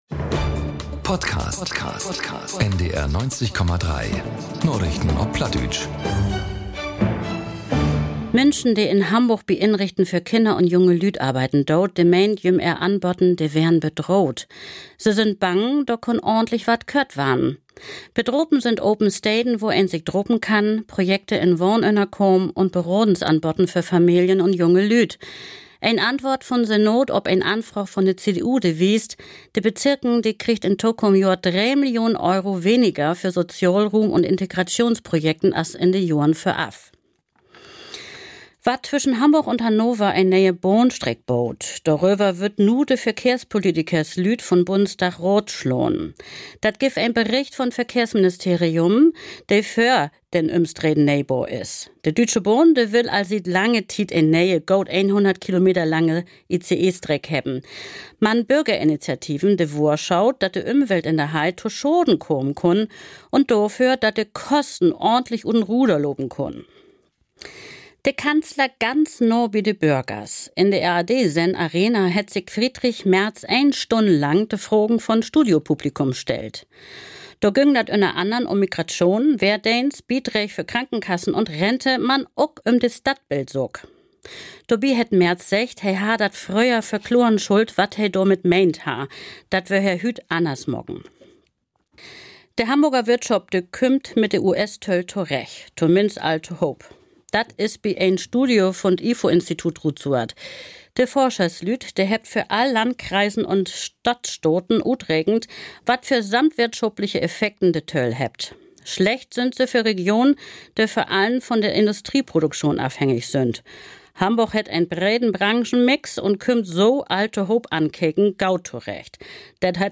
Narichten op Platt 09.12.2025 ~ Narichten op Platt - Plattdeutsche Nachrichten Podcast